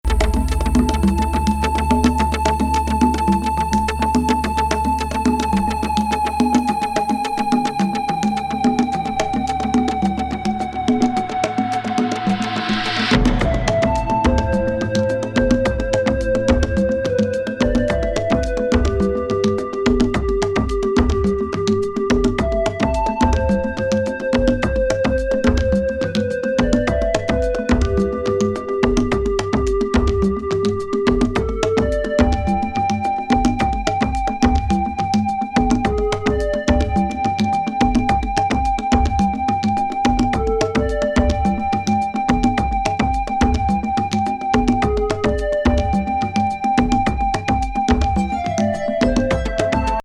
アフロ・パーカッシブ・コズミックなシンセサイザー・エスニック・ニューエイジ・インスト・グルーブ
Japanese,Rock♪試聴ファイルLABEL/PRESS